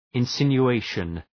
Shkrimi fonetik{ın,sınju:’eıʃən}
insinuation.mp3